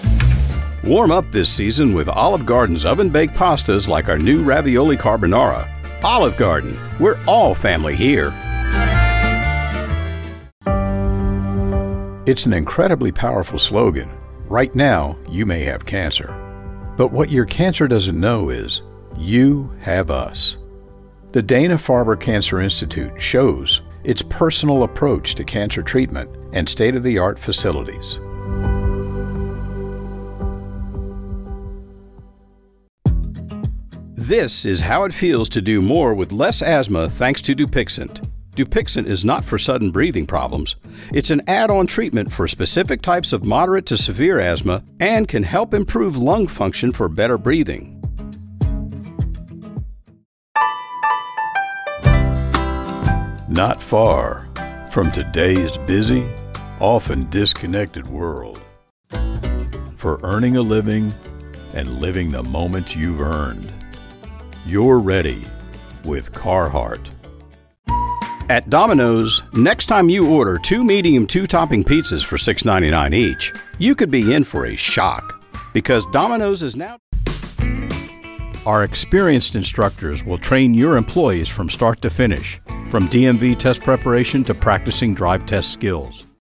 Commercial Demo Reel
English - USA and Canada, English - Southern U.S. English